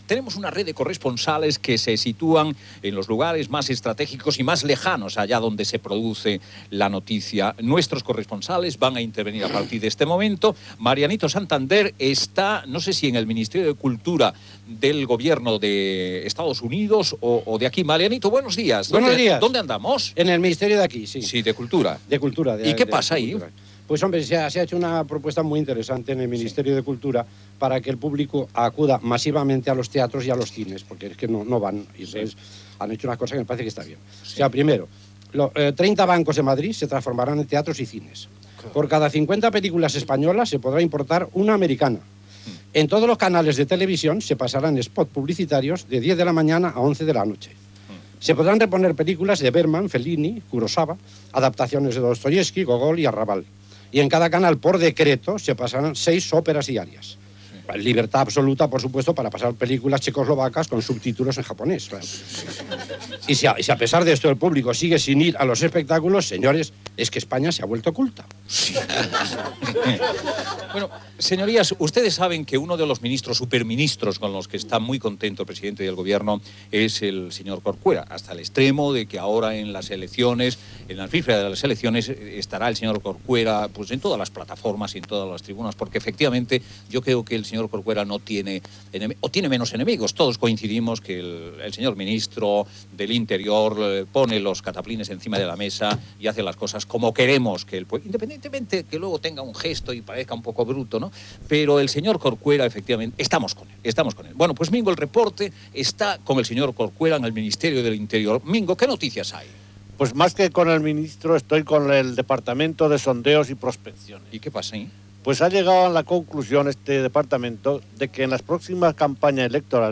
Gènere radiofònic Info-entreteniment Presentador/a Olmo, Luis del
Banda FM